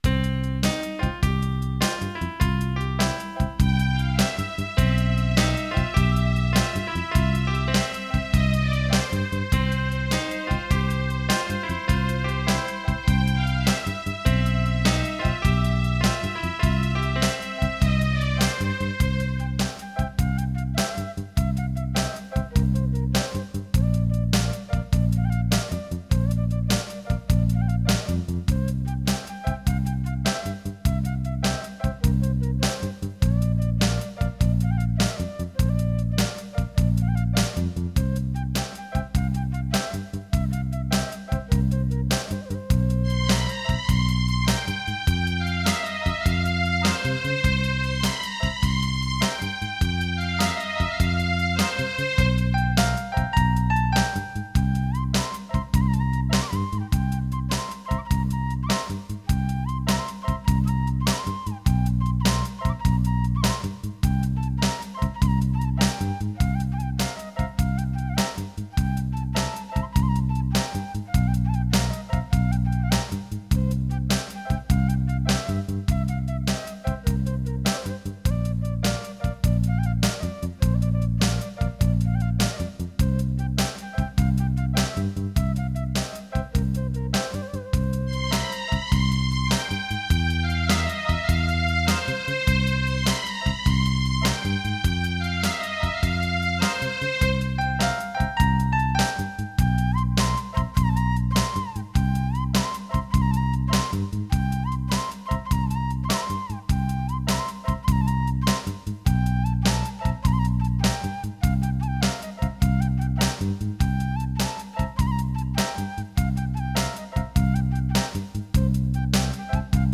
Minus One Tracks